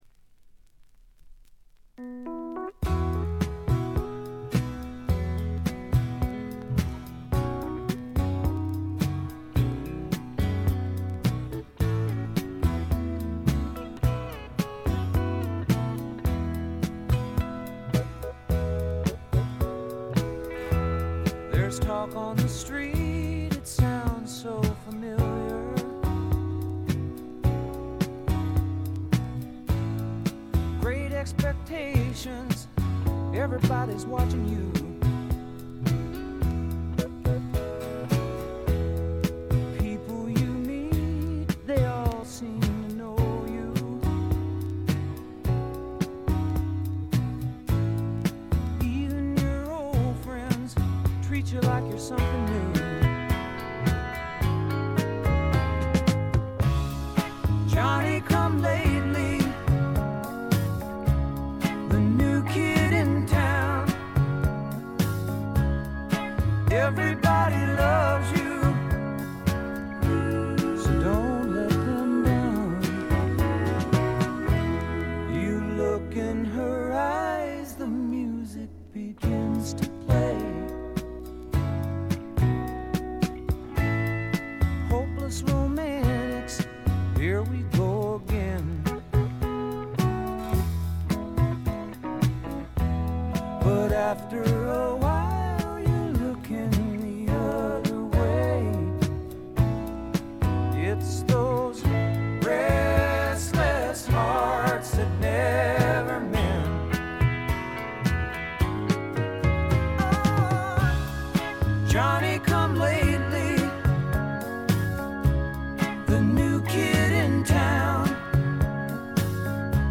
静音部で軽微なバックグラウンドノイズ、チリプチ。
試聴曲は現品からの取り込み音源です。